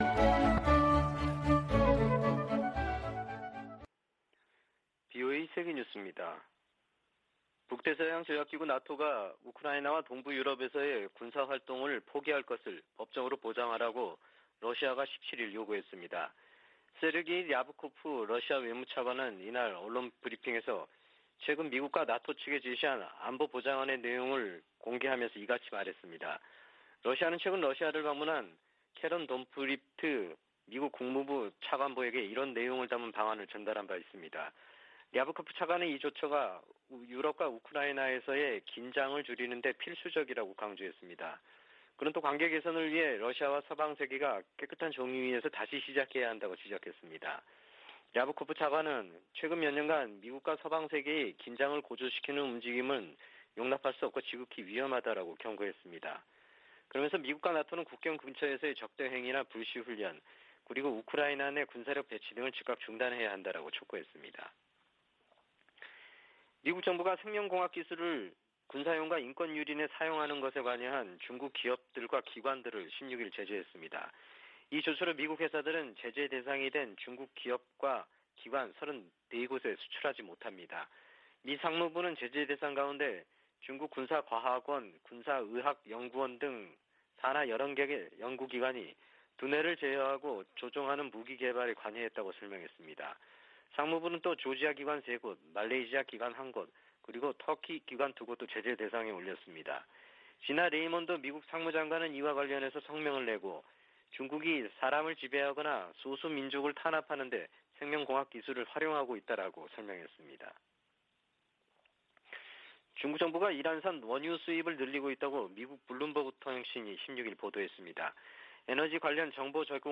VOA 한국어 아침 뉴스 프로그램 '워싱턴 뉴스 광장' 2021년 12월 18일 방송입니다. 미 국무부는 북한이 반복적으로 국제 테러 행위를 지원하고 있다고 보고서에서 지적했습니다. 미국은 북한과 대화와 외교를 통한 한반도의 항구적인 평화를 위해 노력하고 있다고 국무부 부차관보가 밝혔습니다. 미 국무부가 미한 동맹의 중요성을 거듭 확인하면서 중국과 관여하는 문제에도 협력을 강조했습니다.